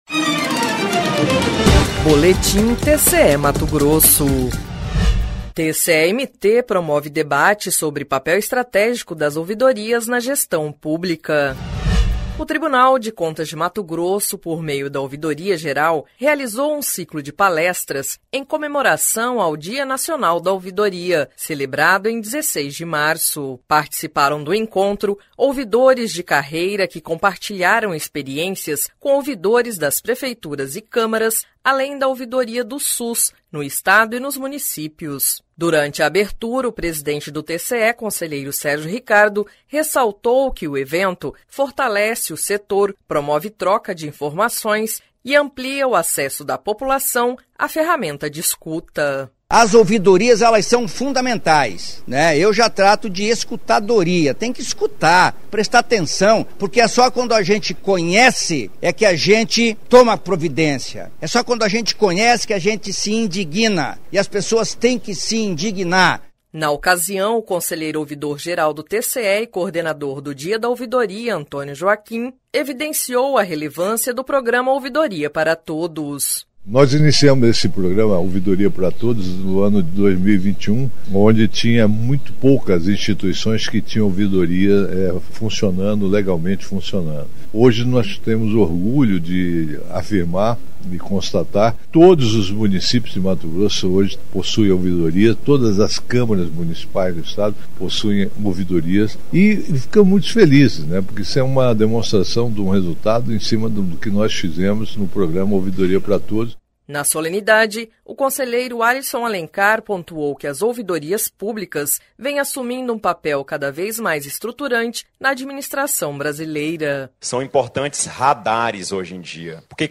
Sonora: Sérgio Ricardo – conselheiro-presidente do TCE-MT
Sonora: Antonio Joaquim - conselheiro ouvidor-geral do TCE e coordenador do evento
Sonora: Alisson Alencar – conselheiro do TCE-MT
Sonora: William Brito Júnior - procurador-geral de contas